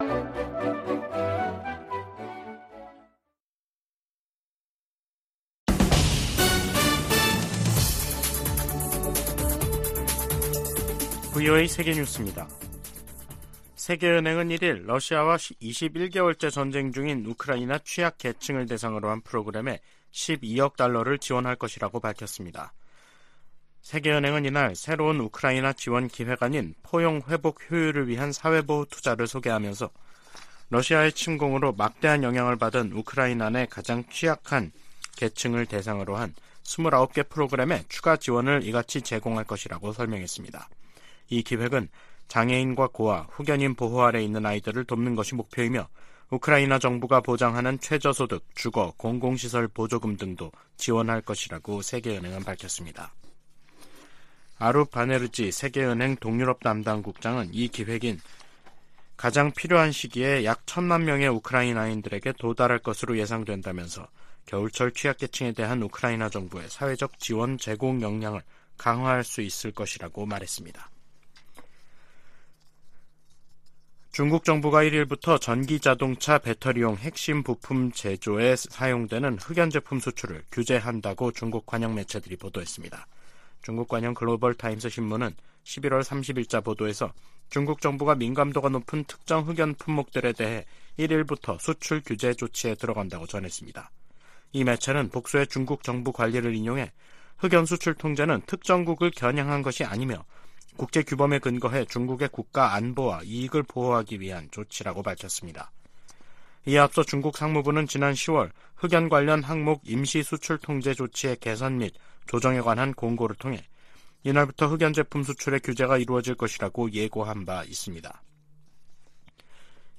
VOA 한국어 간판 뉴스 프로그램 '뉴스 투데이', 2023년 12월 1일 3부 방송입니다. 미국 정부가 북한의 군사 정찰위성 발사에 대한 대응 조치로 북한 국적자 8명과 기관 1곳을 전격 제재했습니다. 한국 정부가 북한의 정찰위성 개발 등에 관련한 북한 사람들에 독자 제재를 발표했습니다. 북한이 유엔 안보리를 선전 도구로 이용하고 있다고 유엔 주재 미국대표부가 비판했습니다.